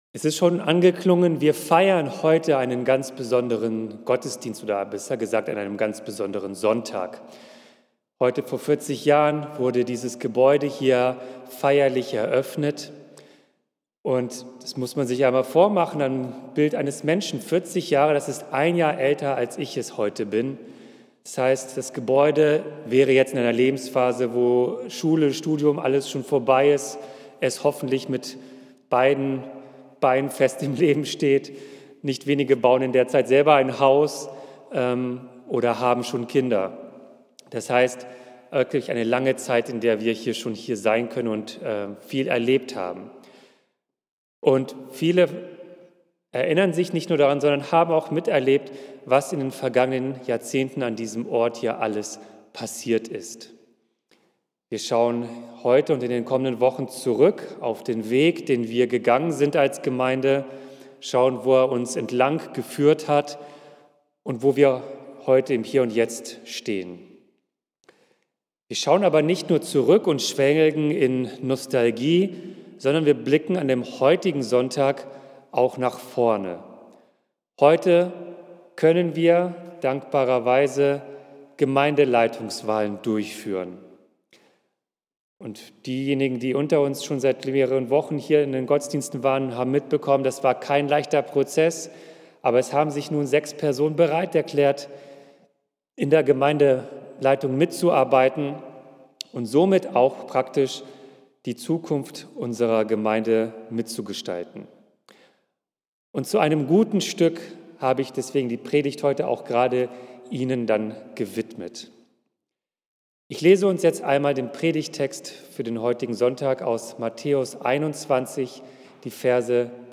Richtig streiten und stehen lassen - Predigt zu Matthäus 21, 23-27 | Bethel-Gemeinde Berlin Friedrichshain